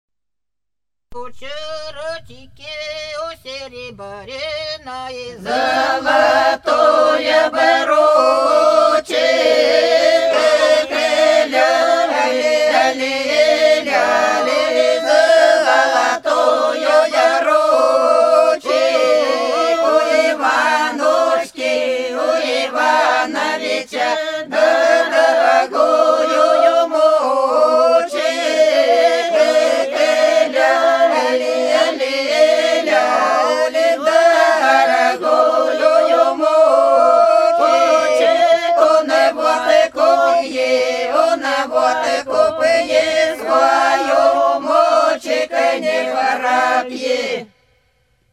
Хороша наша деревня У чарочки у серебряной - свадебная (с. Подсереднее)
23_У_чарочки_у_серебряной_-_свадебная.mp3